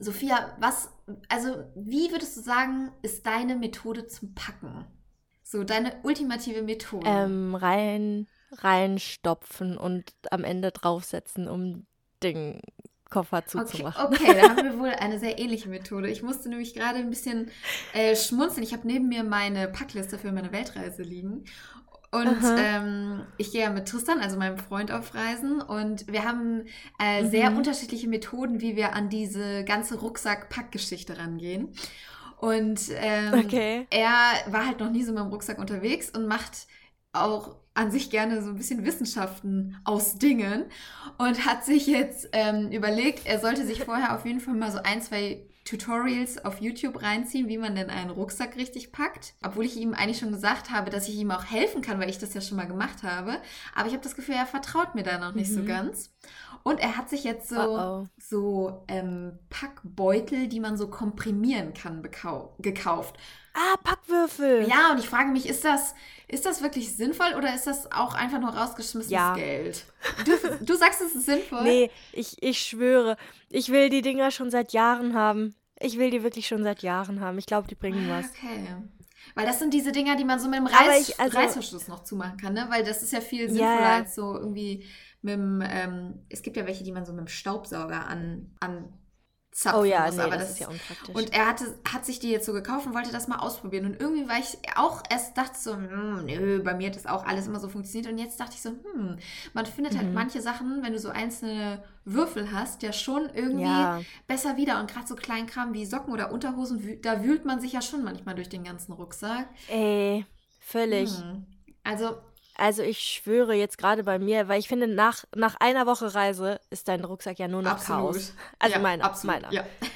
Über erfüllte Träume, Gletscher im Getränk und Pumas in freier Wildbahn: unser Live-Bericht aus Chile.